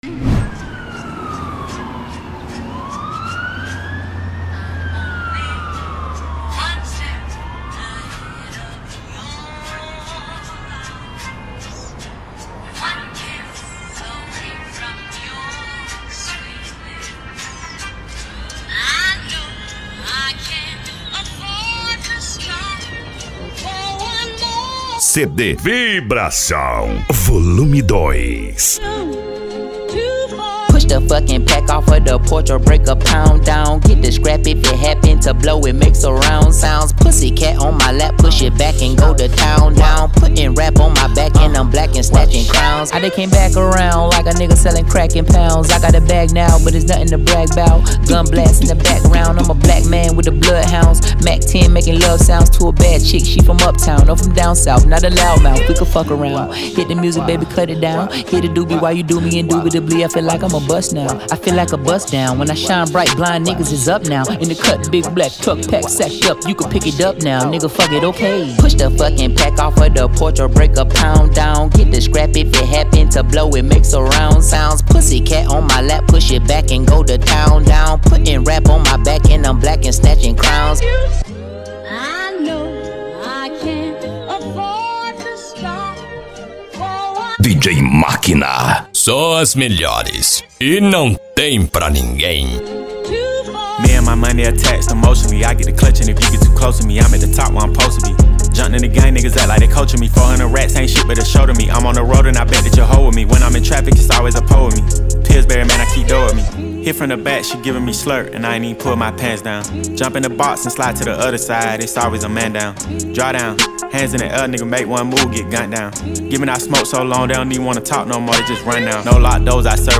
Bass
Eletronica
PANCADÃO